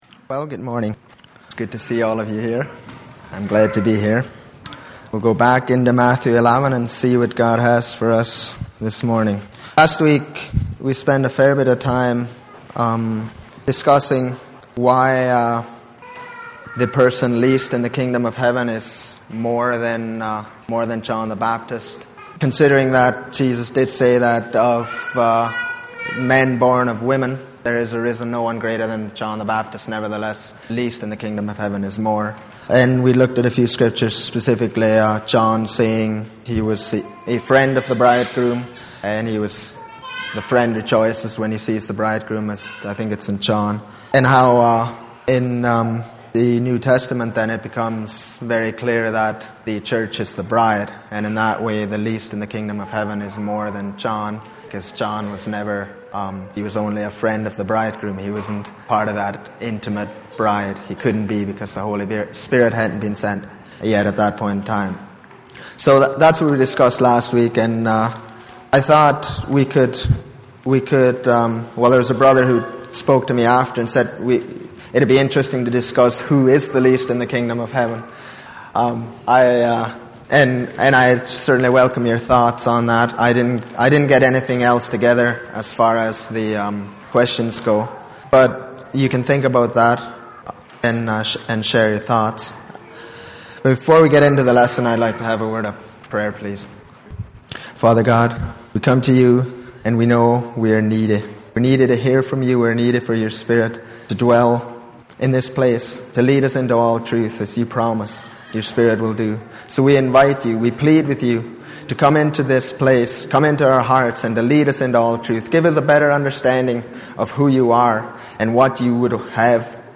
Sunday Morning Bible Study
Service Type: Sunday Morning